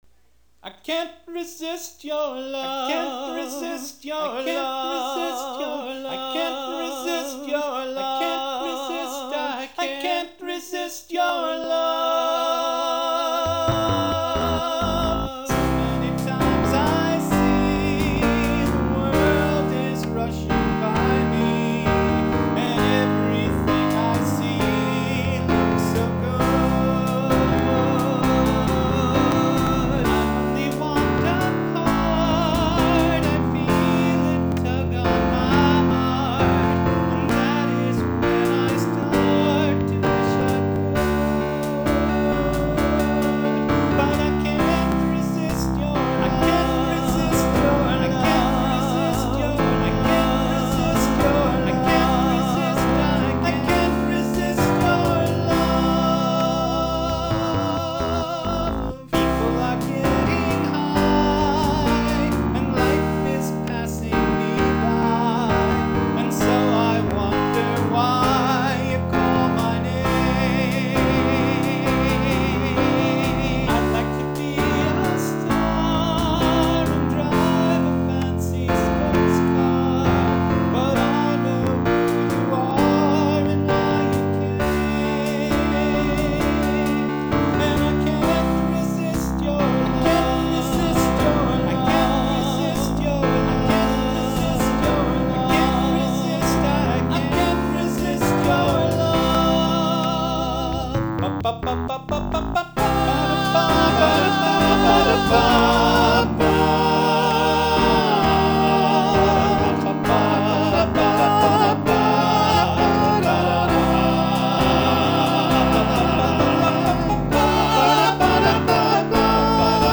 I listed this the number twenty song on my list, and although there are a few places where I didn’t get the vocals exactly right (and embarrassingly it is the tenor–my part–that has the mistakes) I put it number 11 on performance/recording quality, probably largely for the vocals.
It was always envisioned with at least four vocals, which is what is used here, a guitar providing a fifth at the end.
It is a rock anthem, with the intention that the ending chorus would keep repeating more than it does in the recording, but it’s a long song with two instrumental verses (one of them done with contrapuntal vocals) and two bridges, and for the recording I thought it was long enough with four choruses.